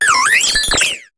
Cri de Grelaçon dans Pokémon HOME.